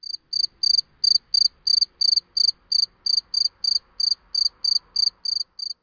crickets.mp3